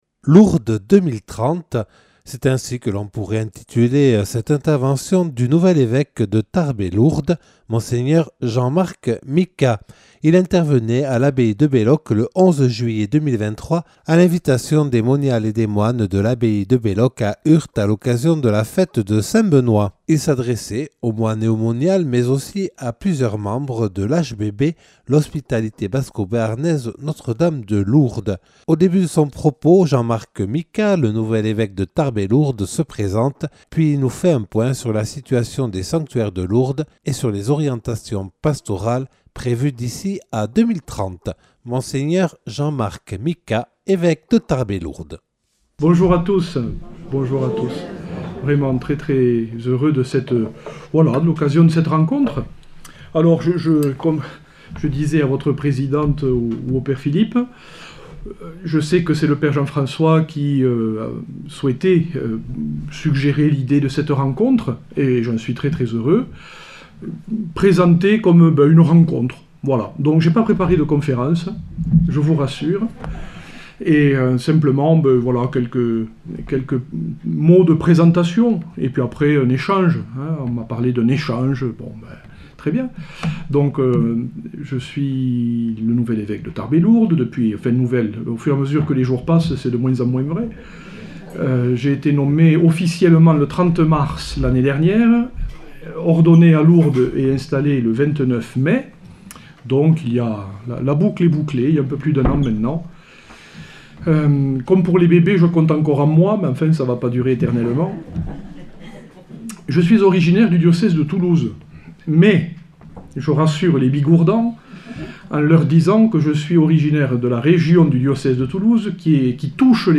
Intervention de Mgr Jean-Marc Micas, évêque de Tarbes et Lourdes enregistrée le 11/07/2023 à l'abbaye de Belloc.